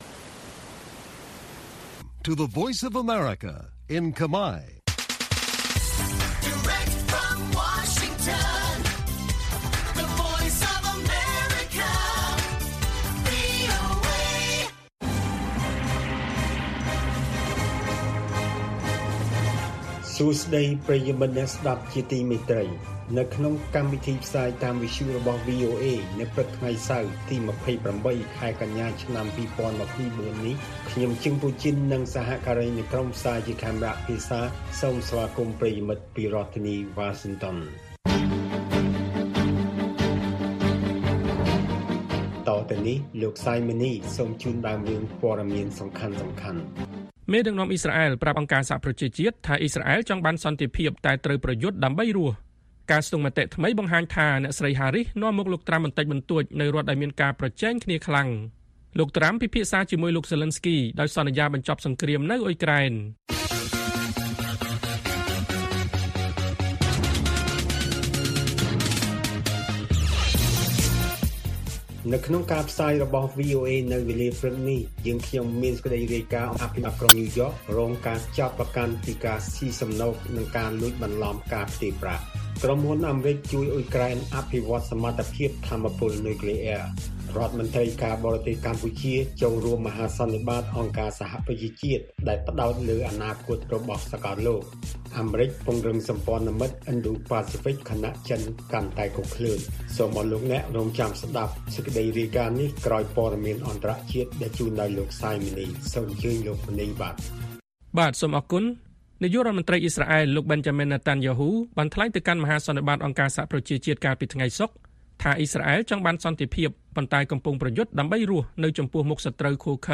ព័ត៌មានពេលព្រឹក ២៨ កញ្ញា៖ មេដឹកនាំអ៊ីស្រាអែលប្រាប់ អ.ស.ប.ថាអ៊ីស្រាអែលចង់បានសន្តិភាពតែប្រយុទ្ធដើម្បីរស់